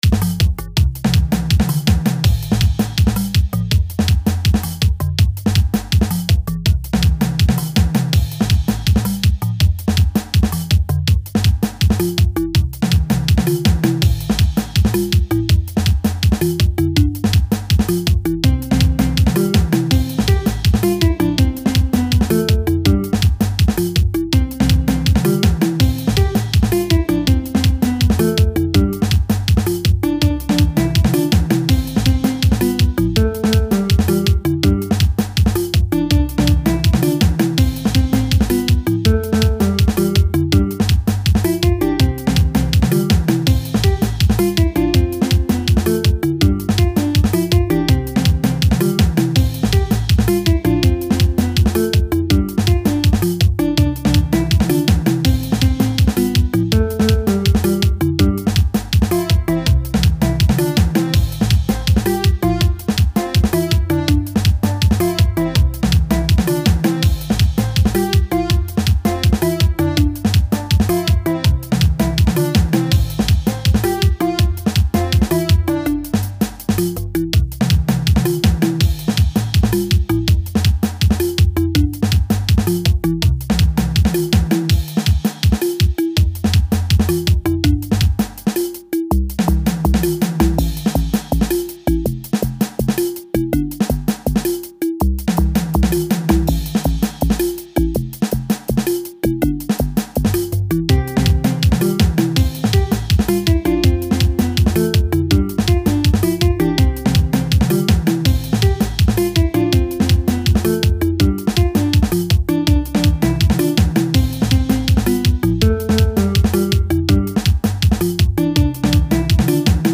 03:26 Genre : Xitsonga Size